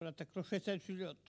Localisation Soullans
Enquête Arexcpo en Vendée
Catégorie Locution